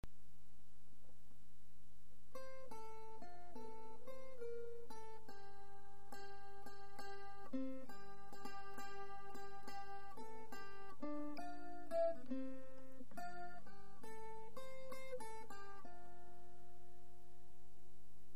Traditionnels